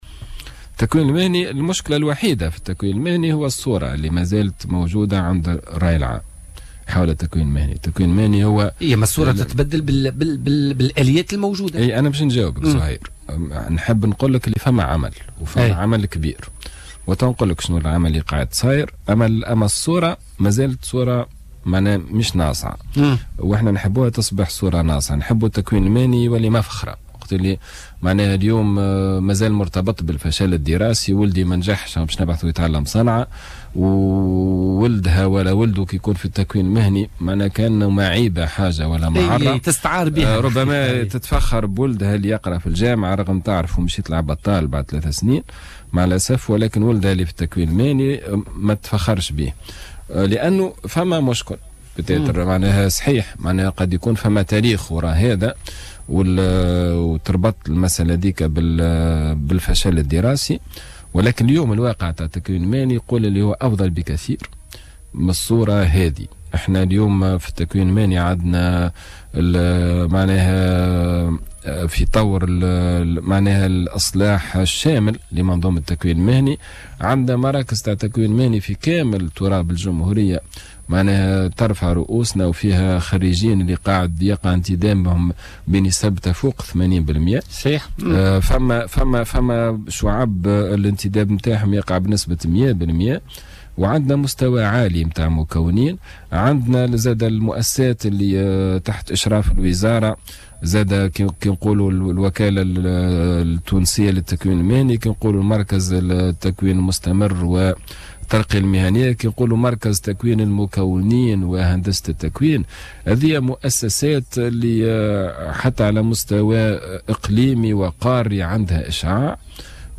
وأوضح ضيف "بوليتيكا" أن هناك اختصاصات مثل اختصاص تقني سامي قد تفتح المجال عند التفوق بها لمواصلة دراسة الهندسة بالجامعة.